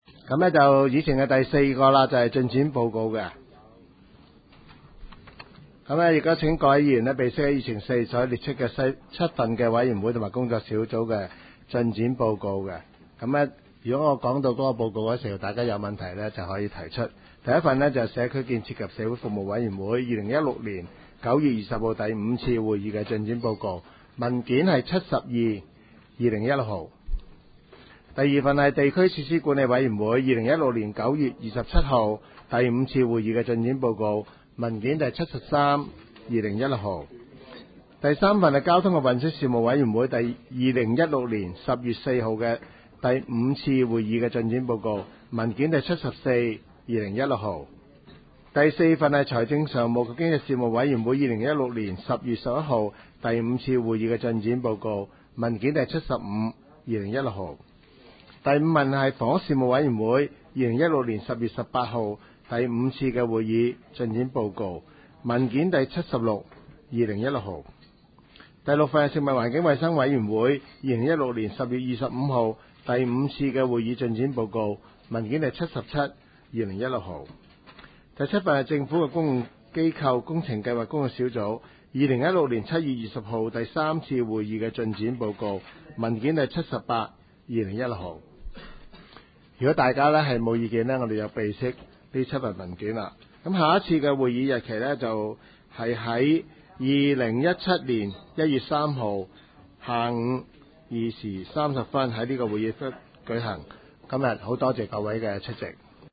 区议会大会的录音记录
黄大仙区议会会议室